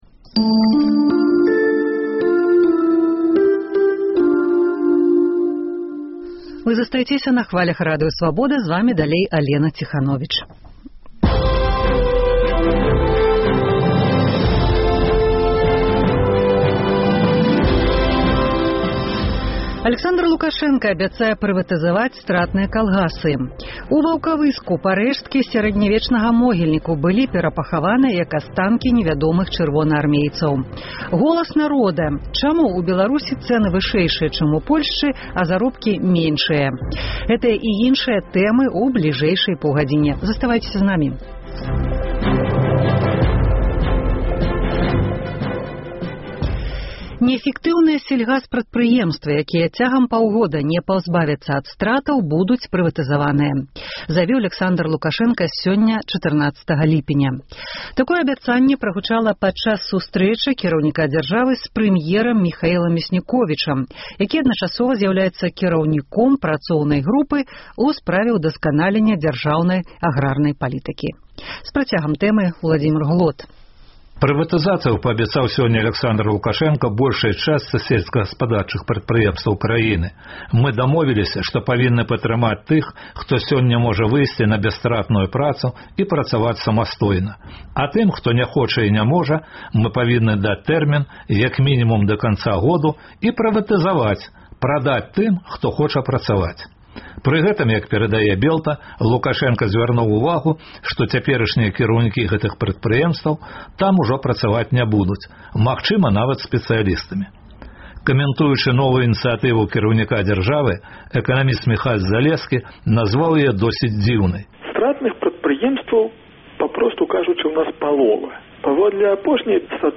Наш карэспандэнт перадае адтуль, дзе ў 1986-м асадзілі радыяцыйную хмару. У Валожыне грамадзкія актывісты праводзяць пікет супраць будаўніцтва жылых дамоў на былым вайсковым стадыёне, месцы расстрэлу габрэяў. Рэпартаж зь месца падзеі.